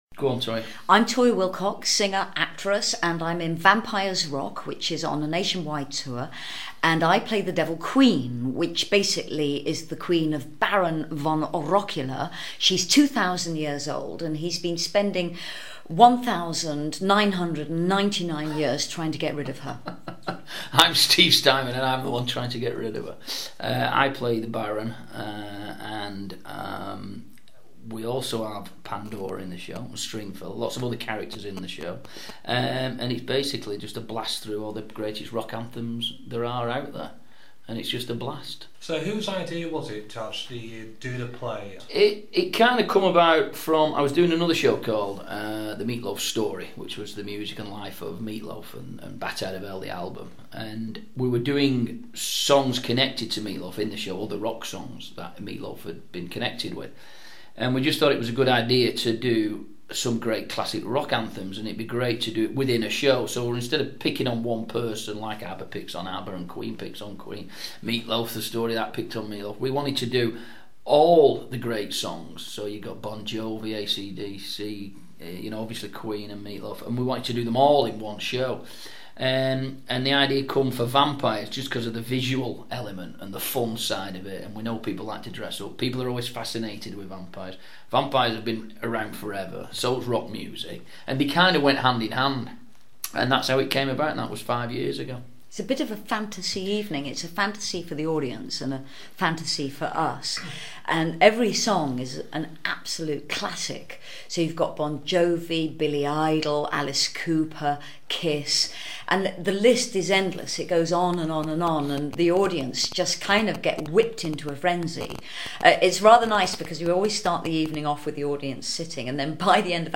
vampia-rock-13min-interview.wma